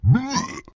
Angry Birds Space Fat Pig Hurt 2